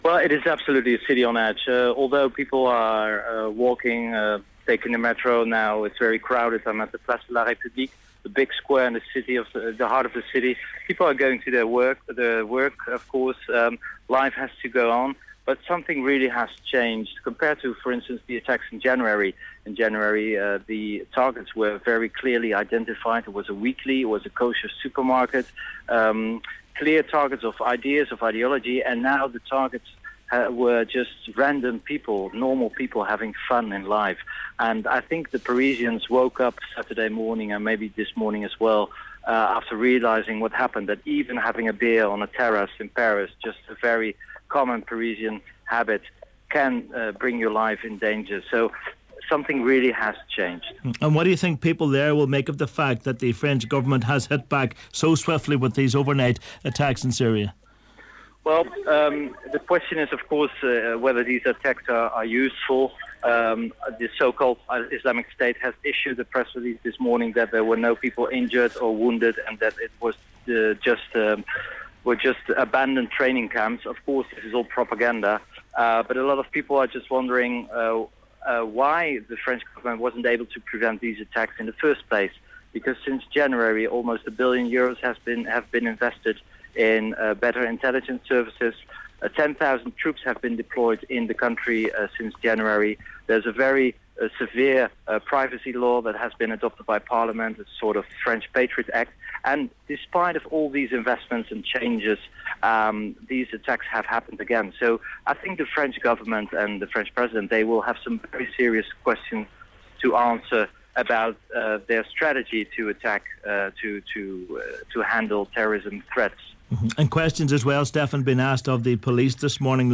French journalist